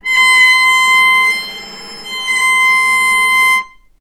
healing-soundscapes/Sound Banks/HSS_OP_Pack/Strings/cello/sul-ponticello/vc_sp-C6-ff.AIF at cc6ab30615e60d4e43e538d957f445ea33b7fdfc
vc_sp-C6-ff.AIF